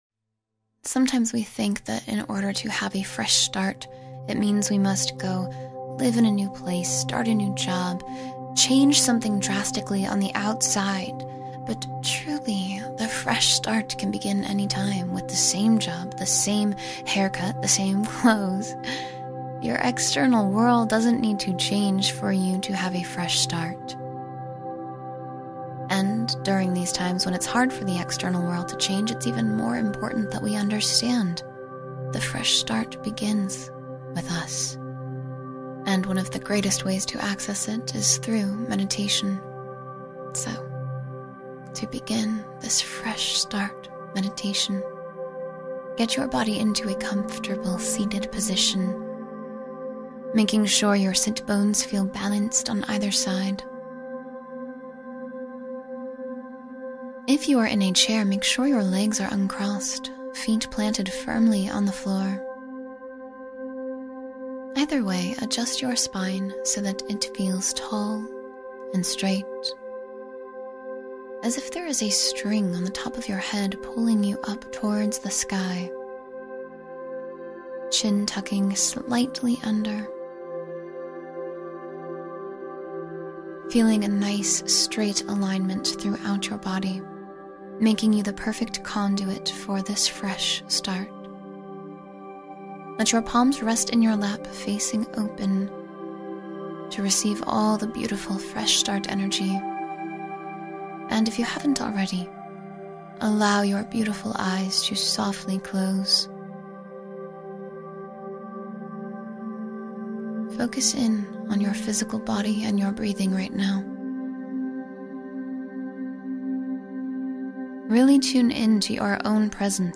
Fresh Start — 10-Minute Morning Meditation to Reset Your Day